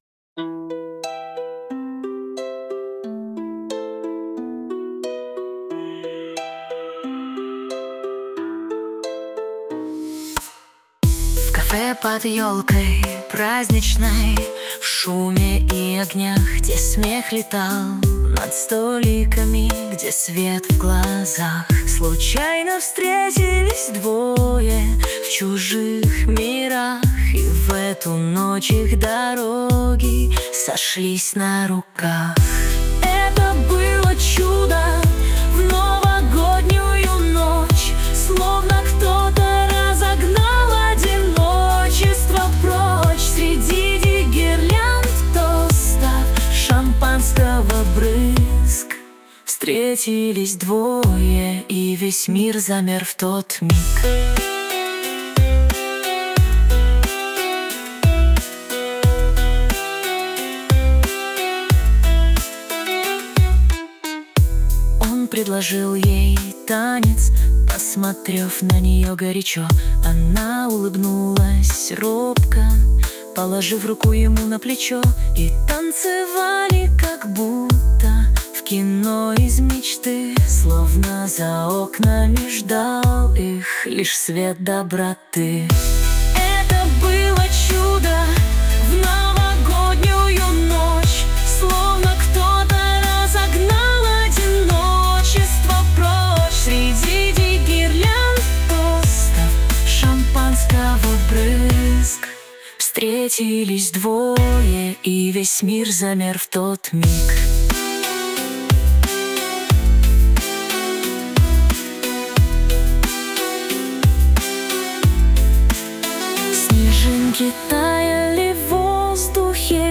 RUS, Romantic, Lyric, Dance, Pop | 16.03.2025 10:35